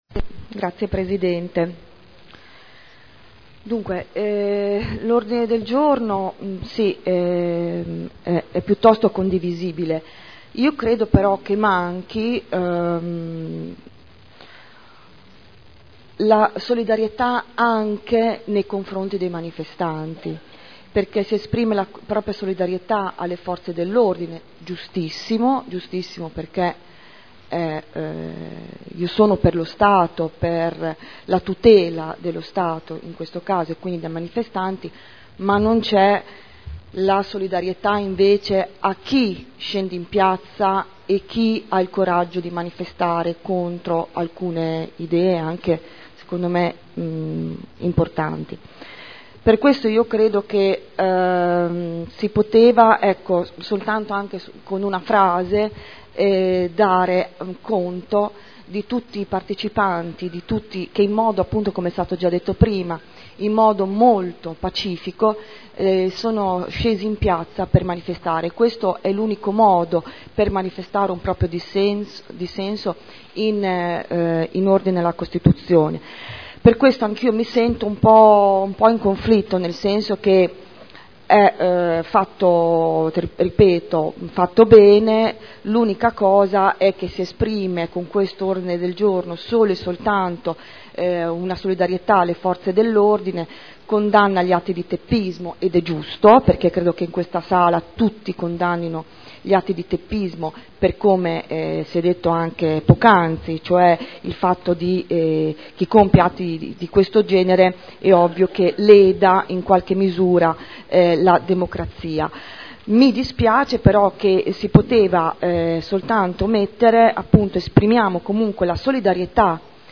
Seduta del 10/12/2012. Dibattito.